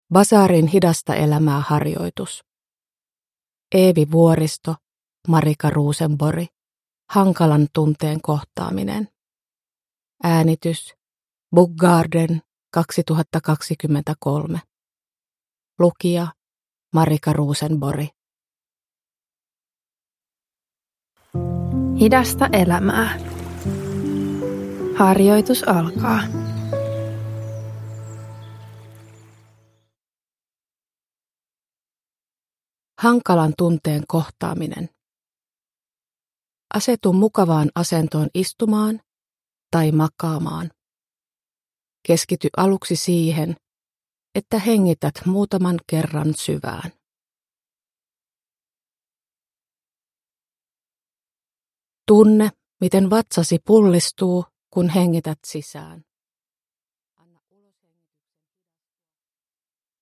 Hankalan tunteen kohtaaminen – Ljudbok
Rauhoittava harjoitus tunnetyöskentelyn avuksi